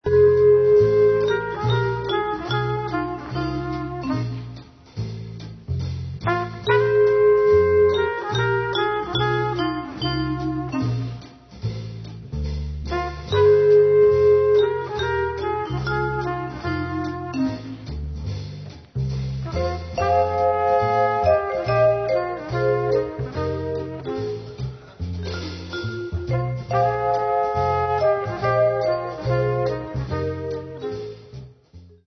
vibes